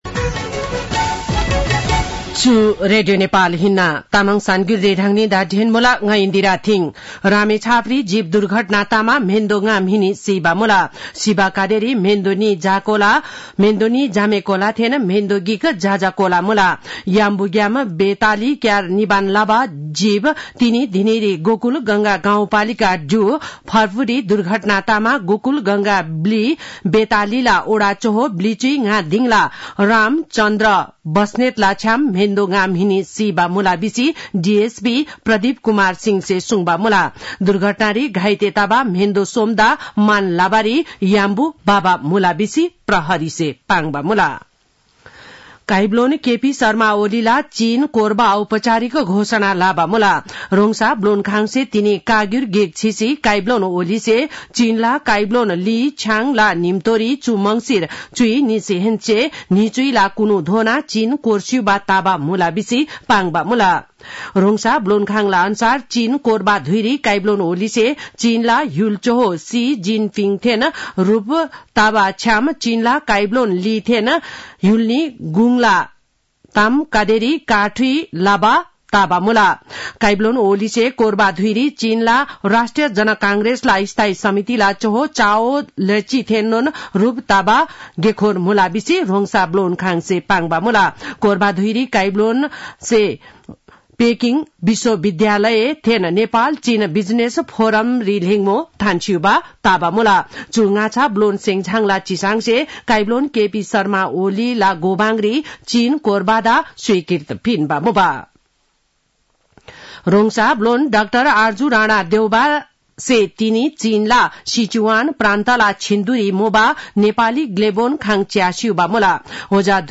तामाङ भाषाको समाचार : १५ मंसिर , २०८१